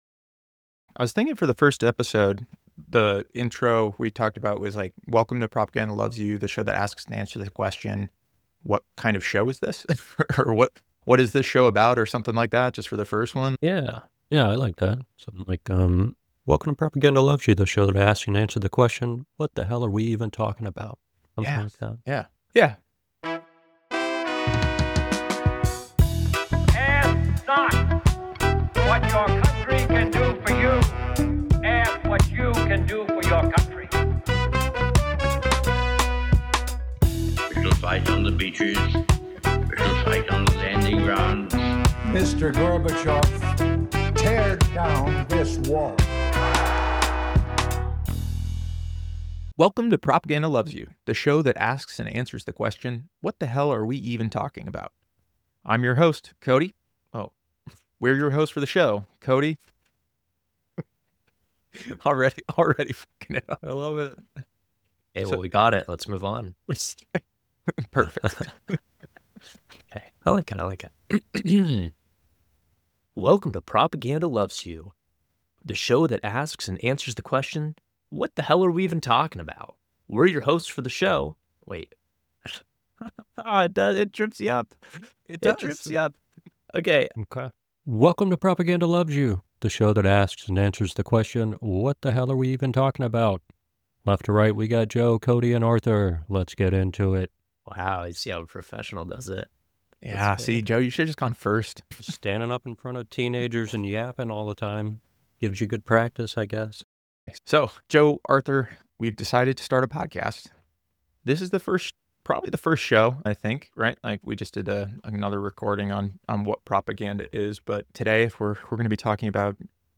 In this introductory episode, learn about their backgrounds, personal biases, and their shared goal of educating listeners on critical thinking and recognizing disinformation. The hosts emphasize the importance of fostering diverse conversations and building a community dedicated to understanding and combating propaganda, while highlighting their shared hope for a better, more informed society.